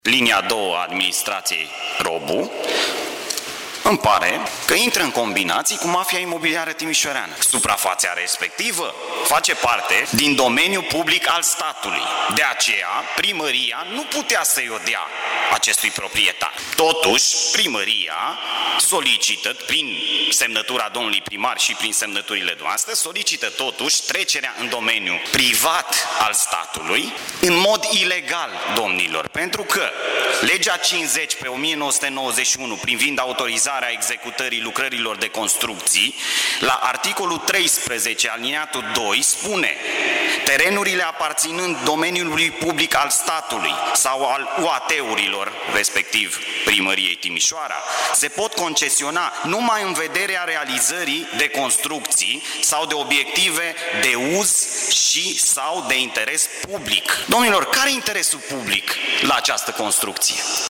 Dezbatere publică pe tema terenului de pe care a fost demolată o casă pe str. Madgearu. Acuzații de ilegalitate